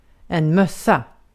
Ääntäminen
UK : IPA : /hæt/